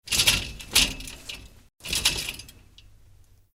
Abrir una cortina
Sonidos: Acciones humanas Sonidos: Hogar